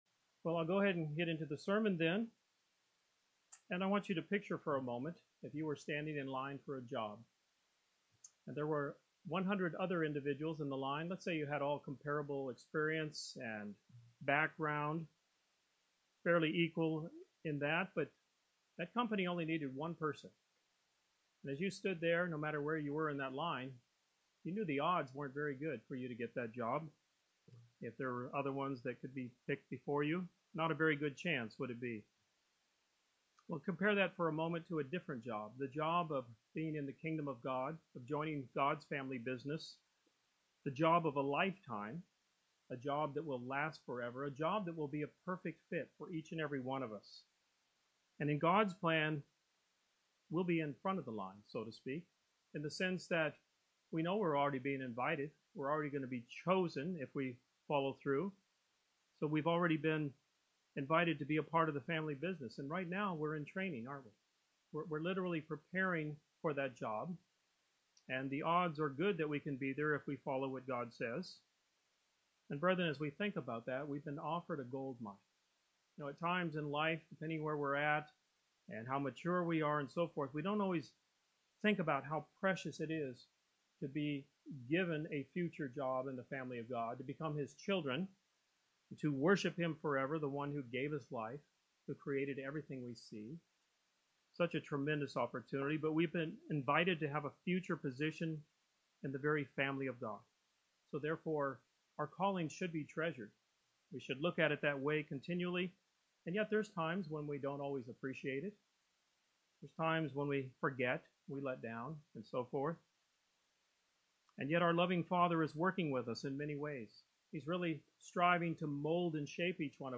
This sermon discusses several points of why a congregation is so important for the members of the body of Christ.